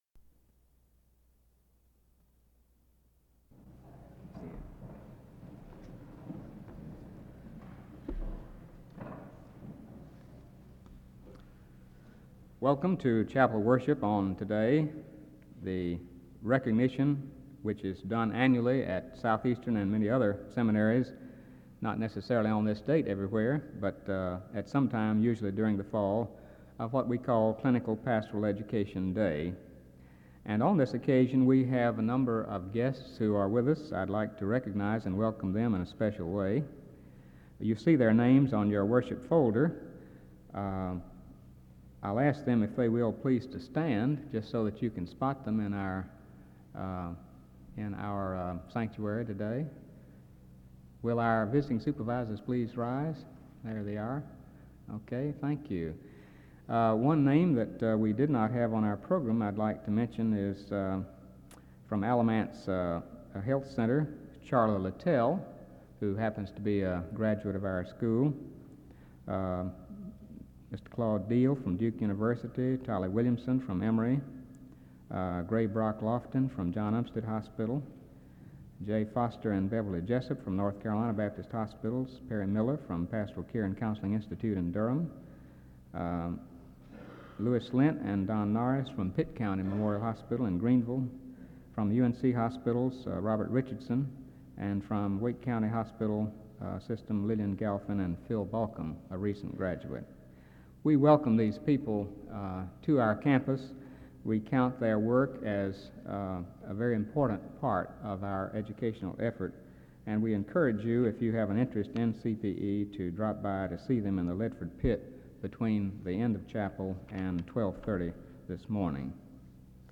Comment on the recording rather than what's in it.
The audio was transferred from audio cassette. This is a special service in honor of Clinical Pastoral Education Day. The service begins with a welcome and opening announcements from 0:11-2:12. There is a closing prayer from 14:35-15:11.